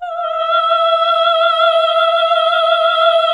AAH E3 -R.wav